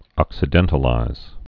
(ŏksĭ-dĕntl-īz)